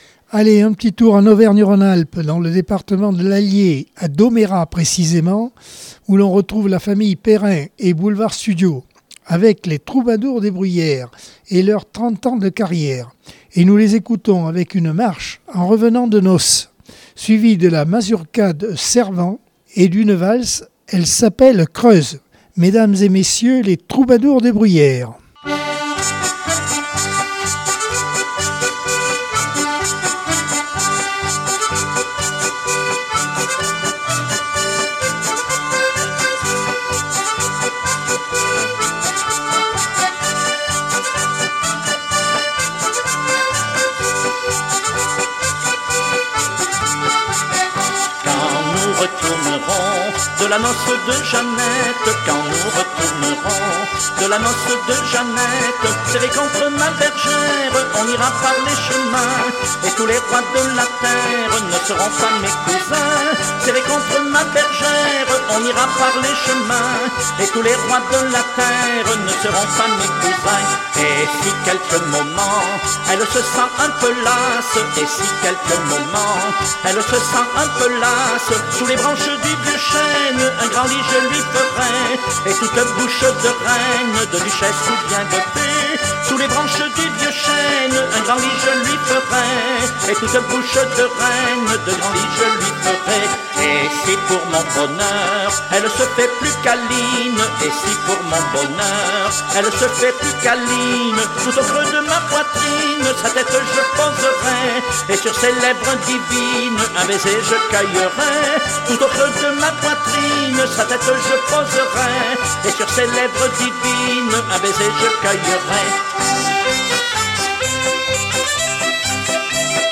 Accordeon 2022 sem 51 bloc 2 - ACX Vallée de la Dordogne